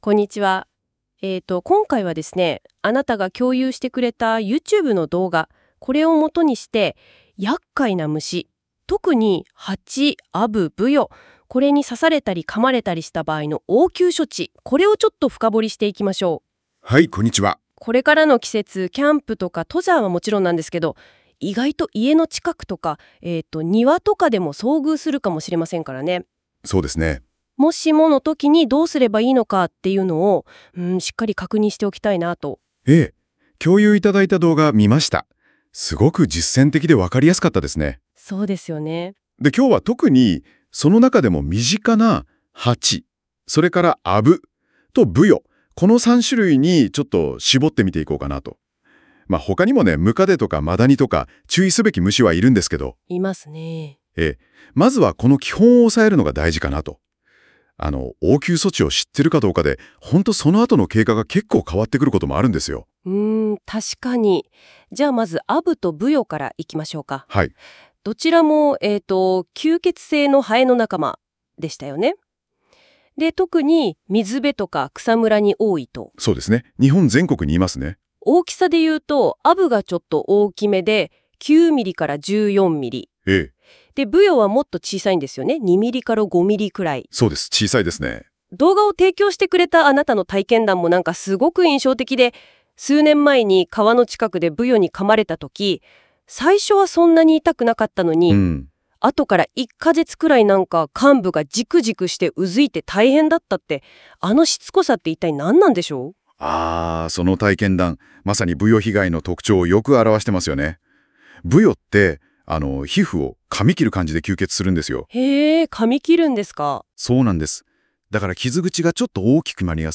↑ポッドキャスト対談の音声はこちら